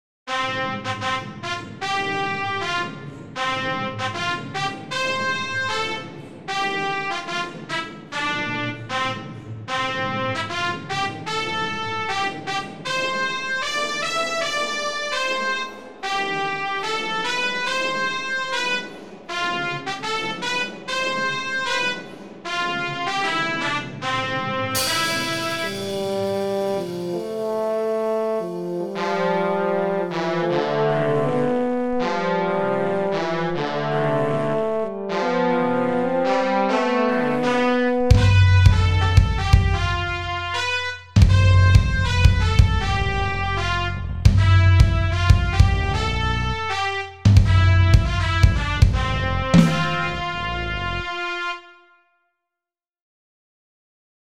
Short Opening Brass Music